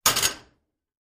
fo_fryingpan_slam_01_hpx
Frying pans are slammed together. Slam, Frying Pan Rattle, Frying Pan Lid, Frying Pan